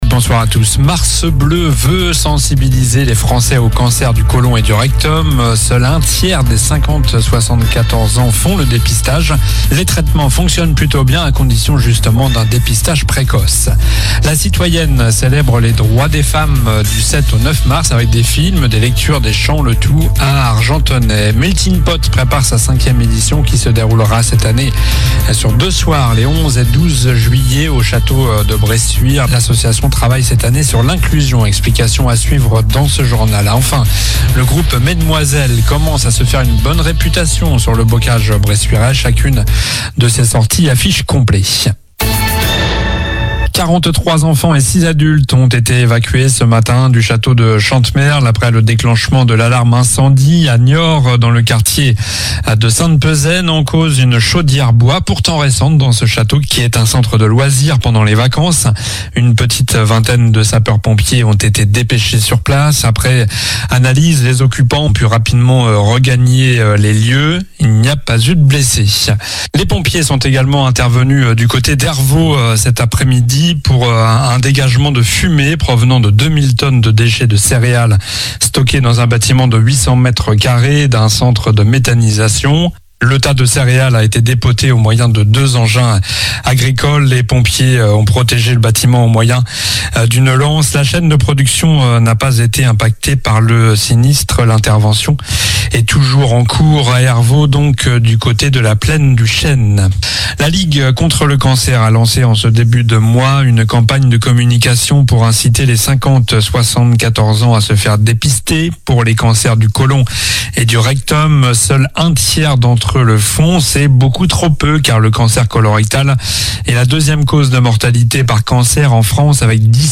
Journal du mardi 04 mars (soir)